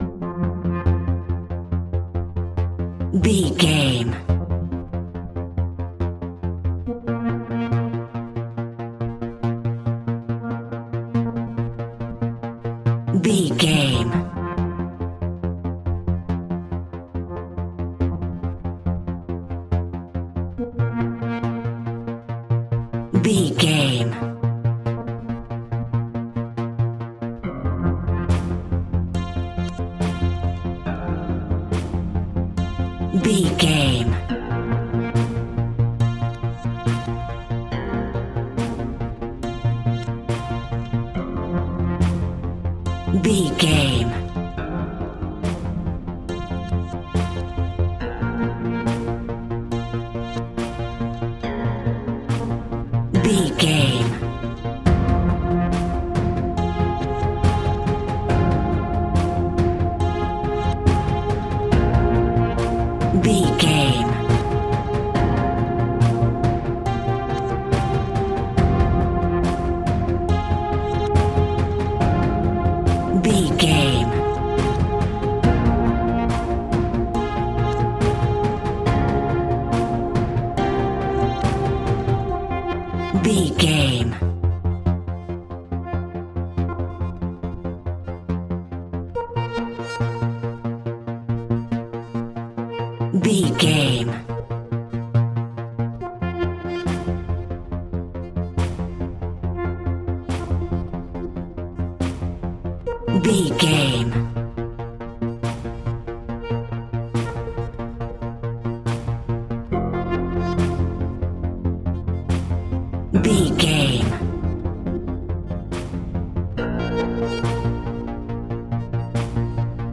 In-crescendo
Thriller
Aeolian/Minor
scary
ominous
dark
haunting
eerie
synthesizer
horror music
Horror Synths